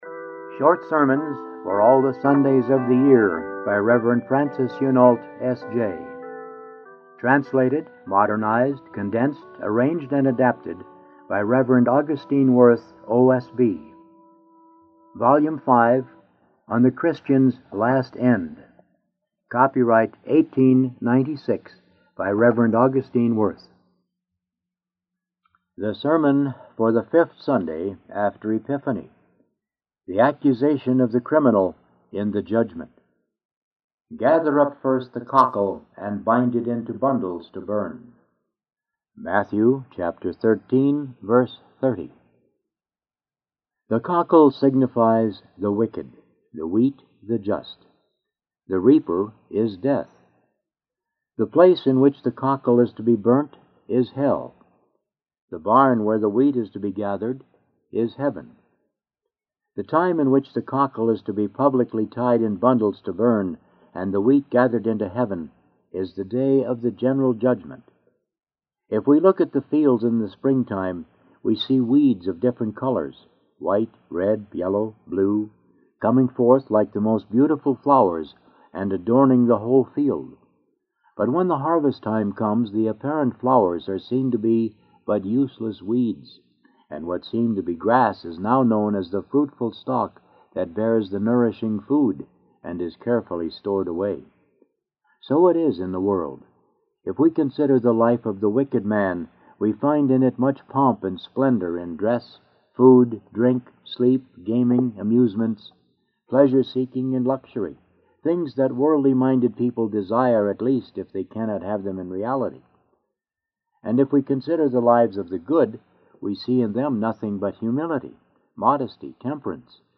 The Impure Tongue Abridged sermon 🎧 Download MP3 | 📄 Download PDF 2.